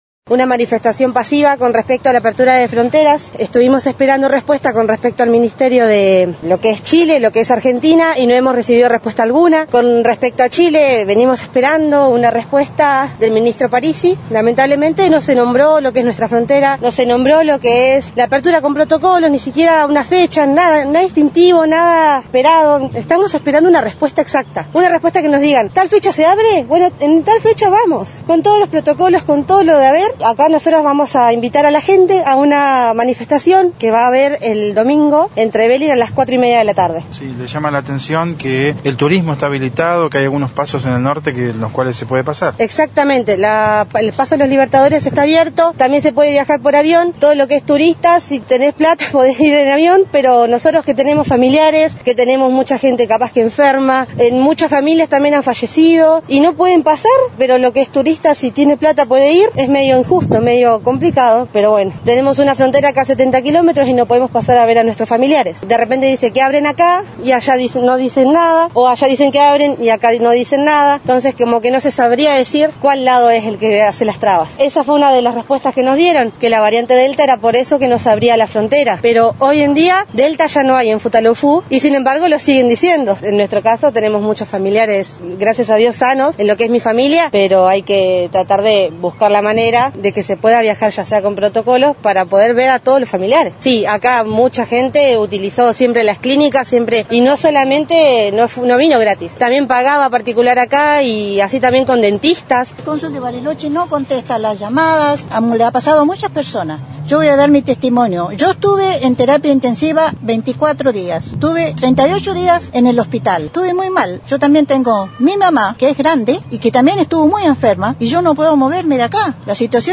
brindaron una conferencia de prensa para invitar a esta concentración y explicaron los motivos por los cuales piden que se abra la frontera.